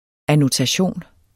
Udtale [ anotaˈɕoˀn ]